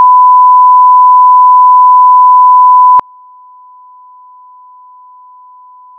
1000Hzの音データ/基準音と低減音 [会話音[女性の声]など] 2000Hzの音データ/基準音と低減音[警報音など]
基準音2秒 → 透過損失された音2秒 を聞くことができます。